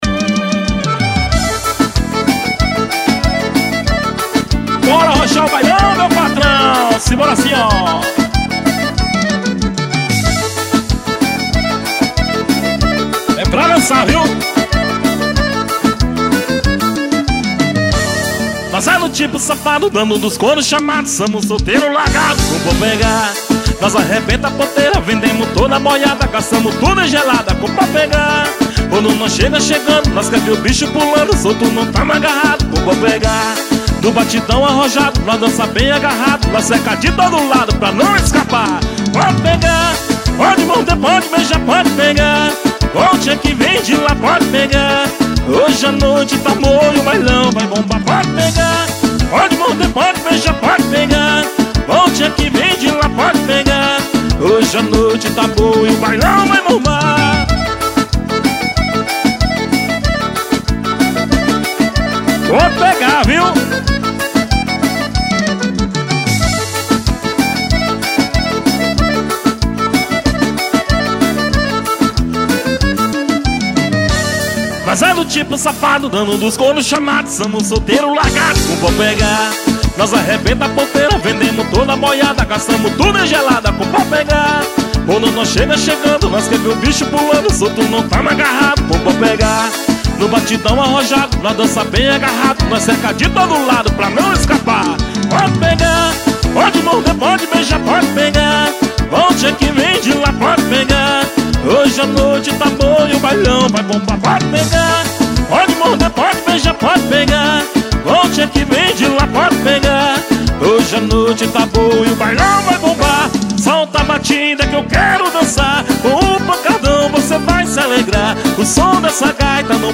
CD AO VIVO.